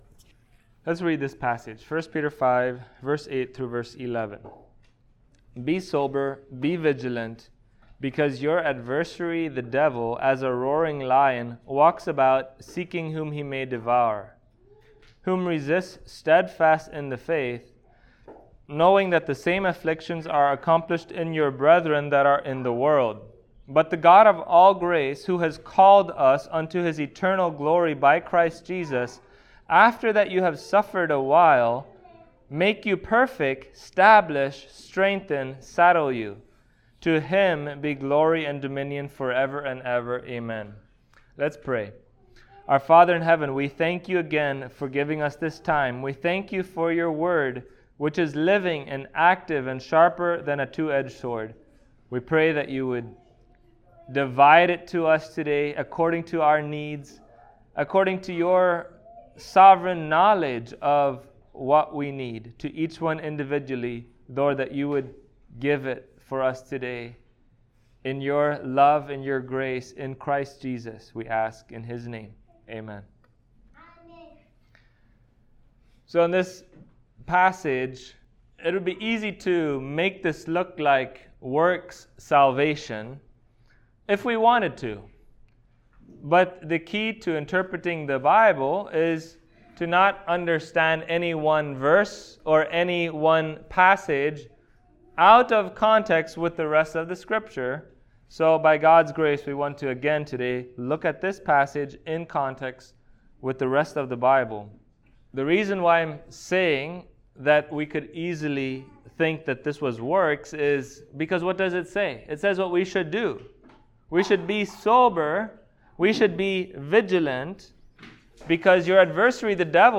Passage: 1 Peter 5:8-11 Service Type: Sunday Morning